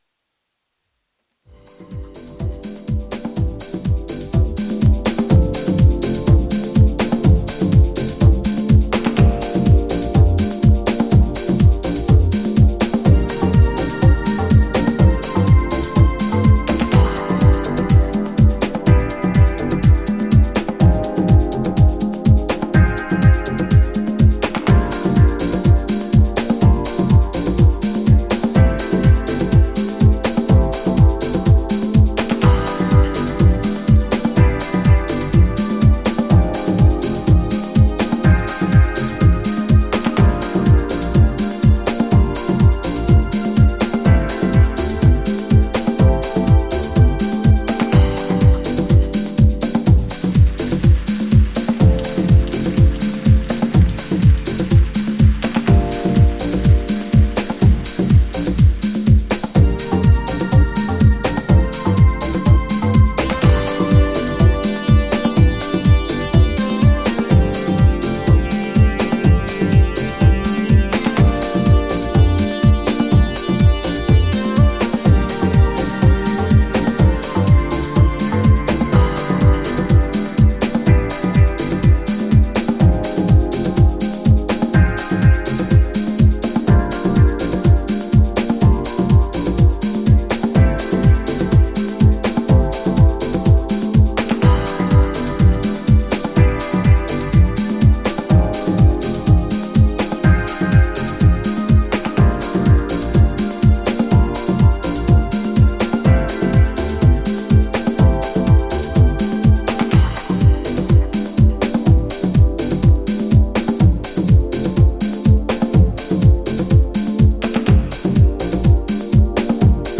INSTRUMENTAL
ジャンル(スタイル) SOULFUL HOUSE / JAZZY HOUSE